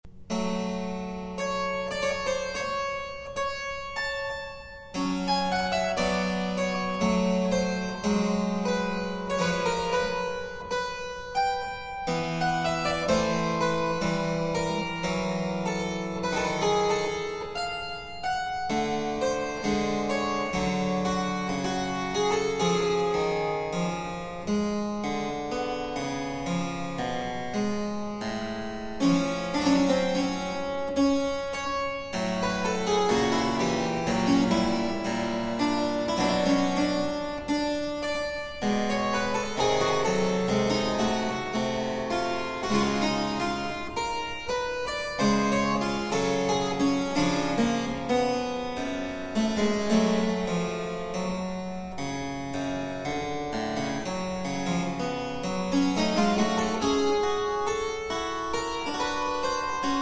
solo harpsichord work
Harpsichord